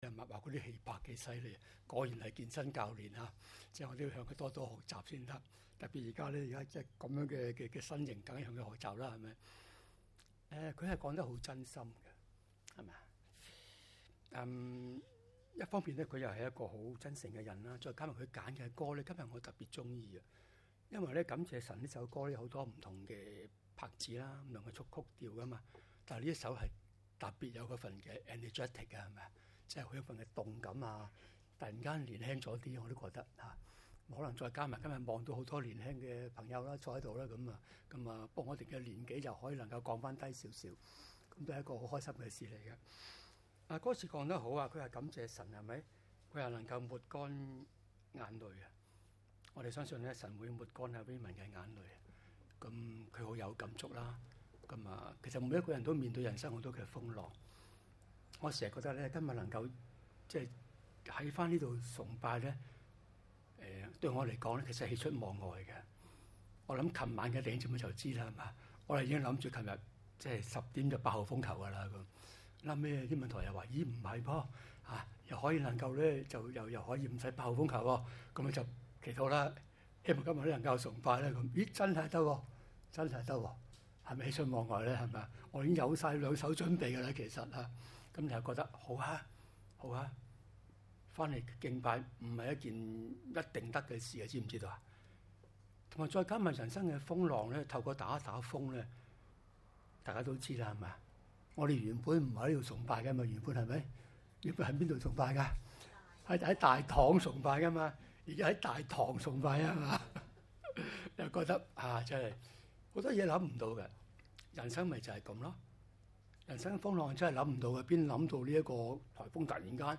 崇拜